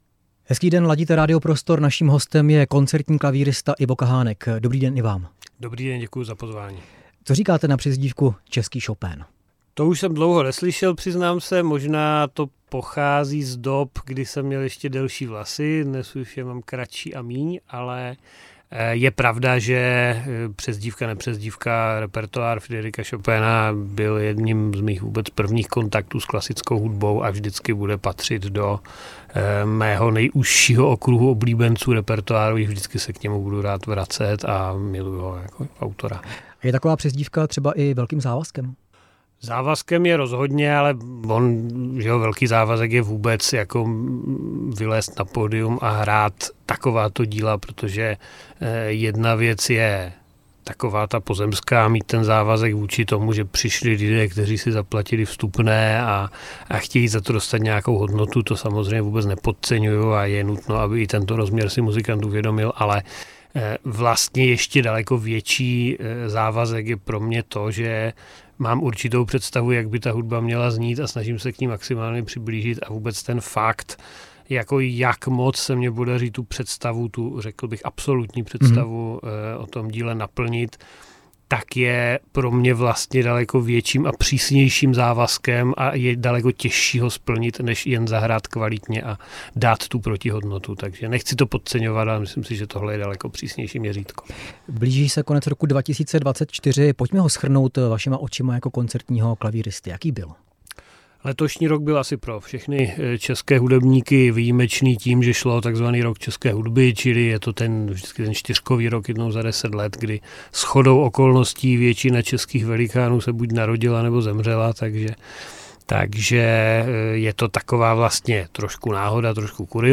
Ivo Kahánek hostem ve vysílání Radia Prostor